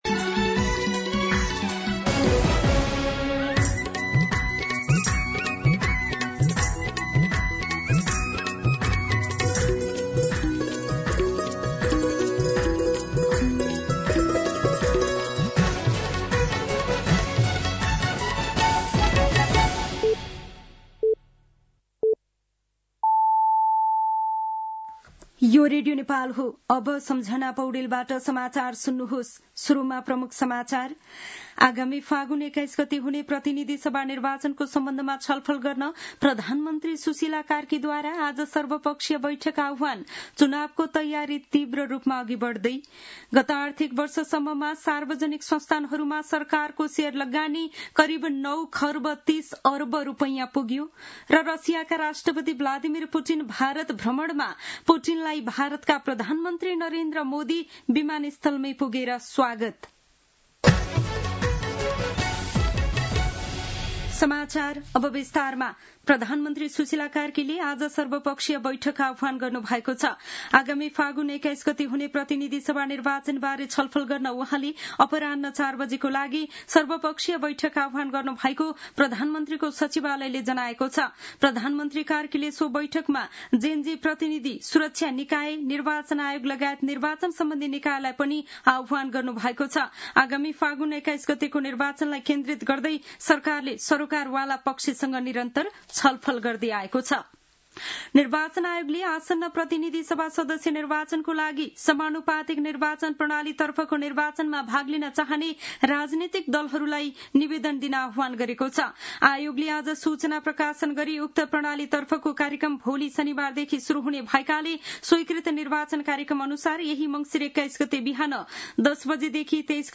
दिउँसो ३ बजेको नेपाली समाचार : १९ मंसिर , २०८२